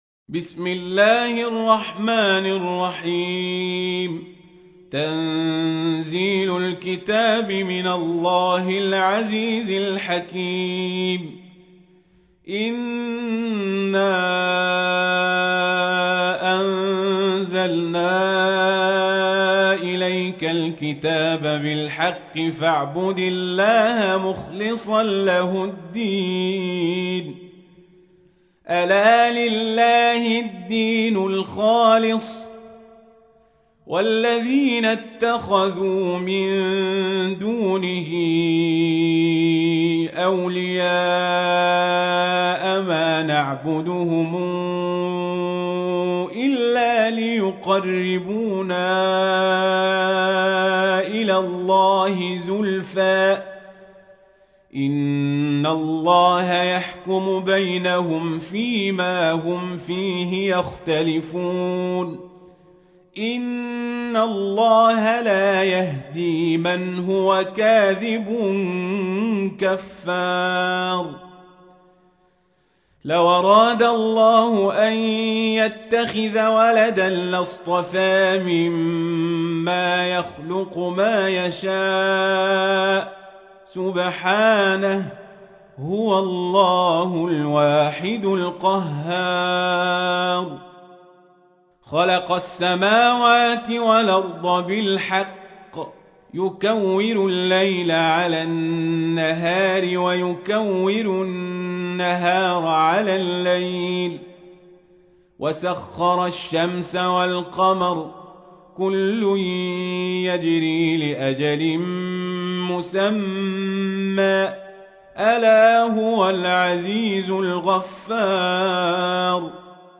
تحميل : 39. سورة الزمر / القارئ القزابري / القرآن الكريم / موقع يا حسين